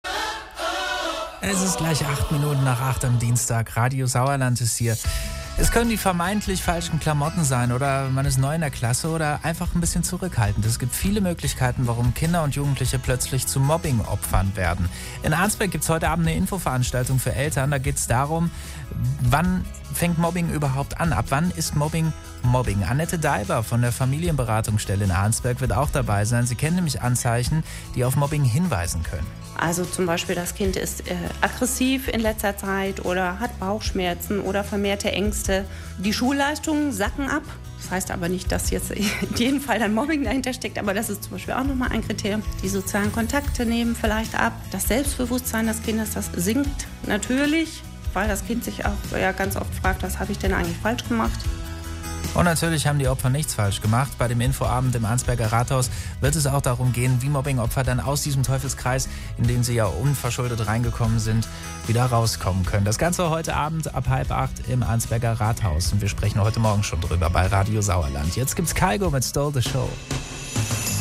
• 21.02.2017 Audioankündigung Radio Sauerland Elterninfoabend Mobbing an Schulen